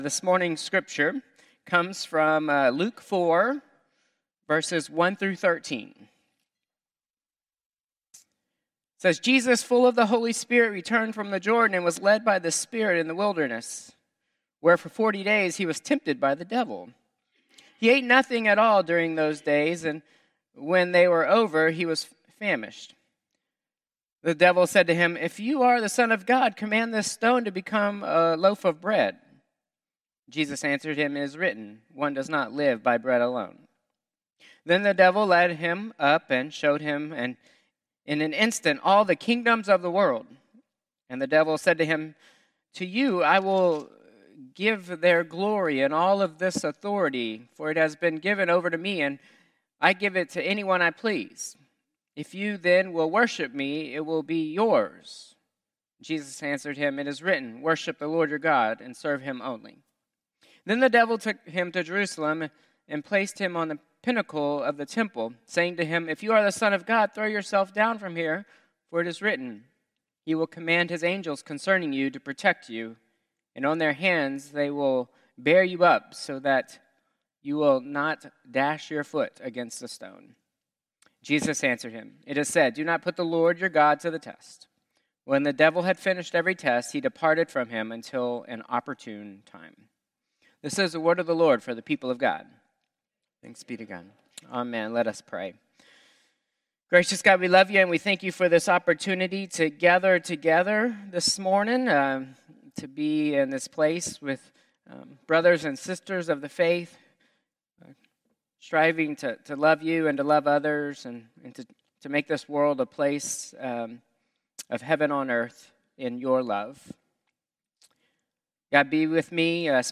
Contemporary Service 3/9/2025